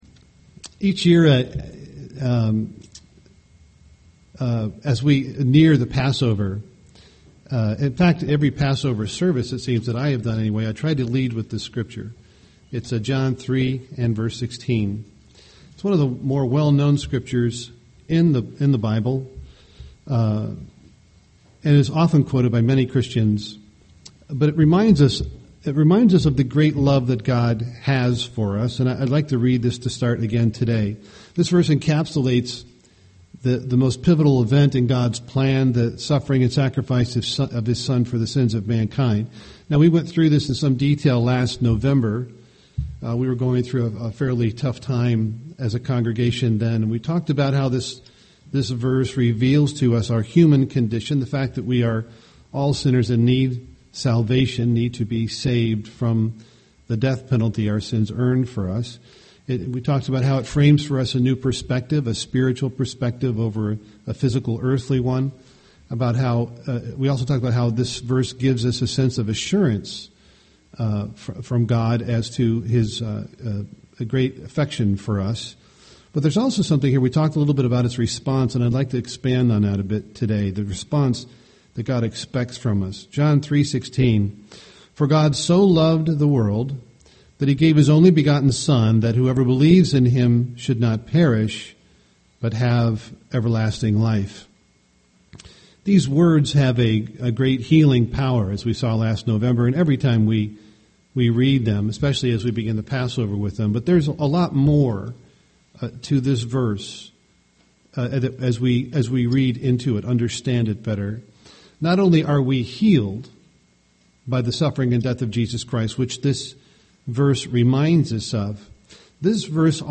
UCG Sermon Christ's sacrifice God’s Love Studying the bible?